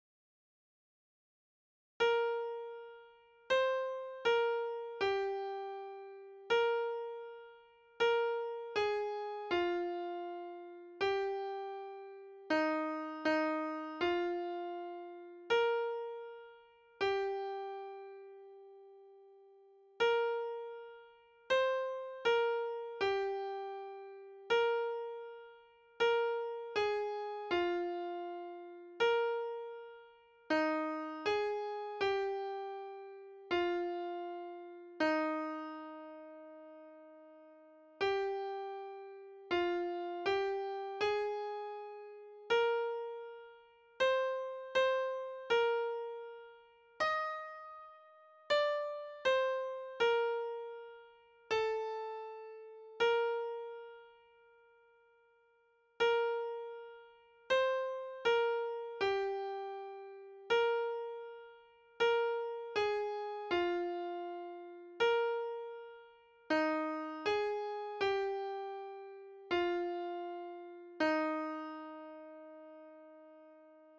Sopran